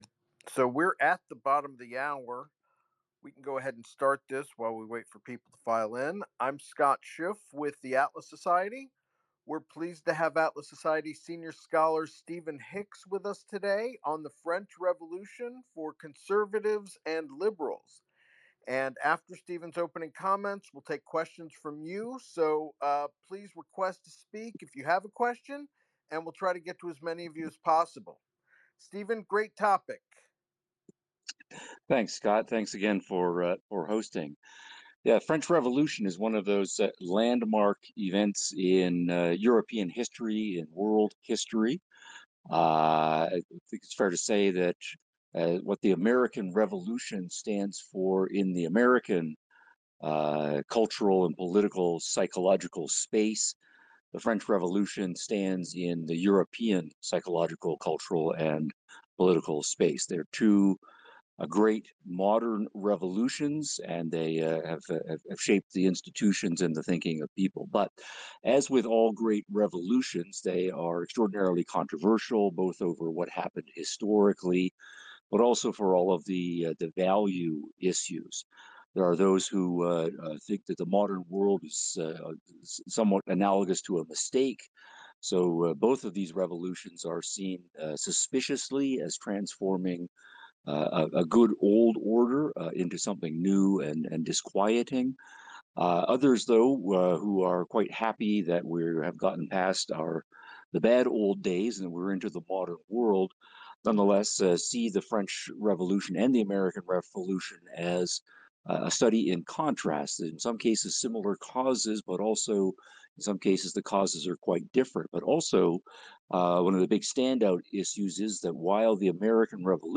Ph.D. for a Spaces discussion on X outlining the main phases of the French Revolution and highlighting its relevance to our philosophical and political arguments today.